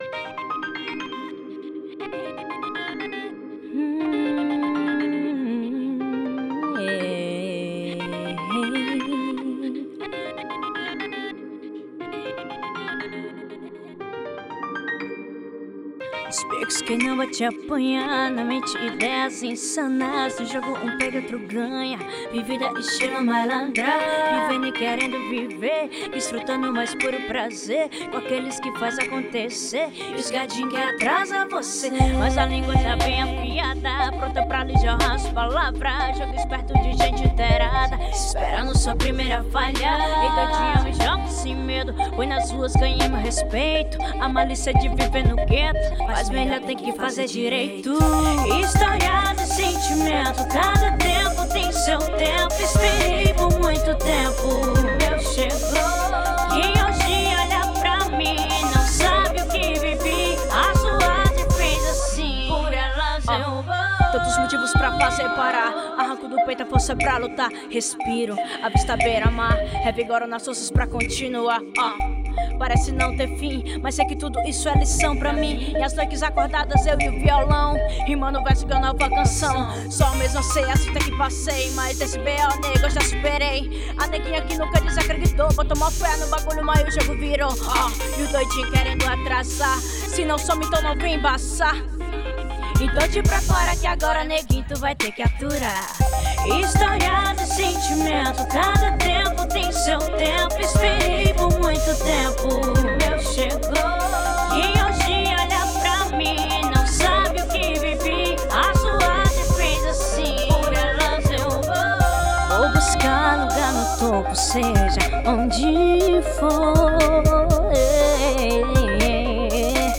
Com a negritude presente em sua identidade mistura diversas vertentes como rap, reggae, r & b, samba, jazz e soul.